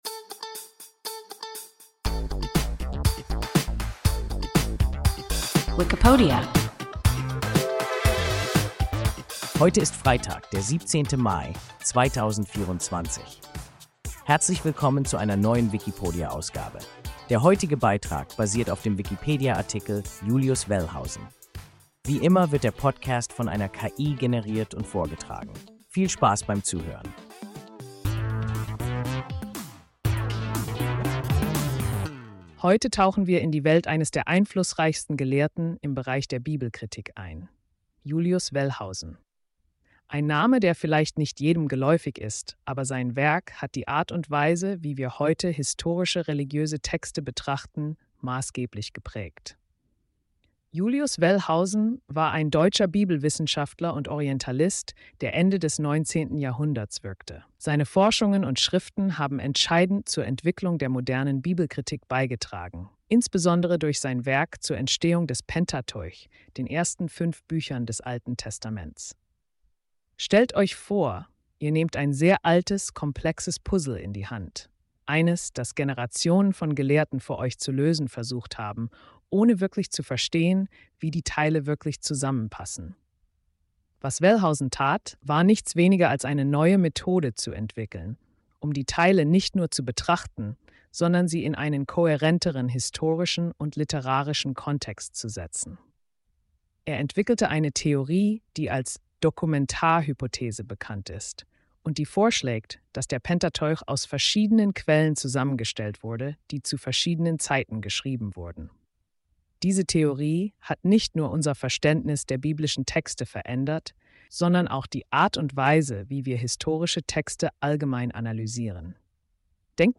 Julius Wellhausen – WIKIPODIA – ein KI Podcast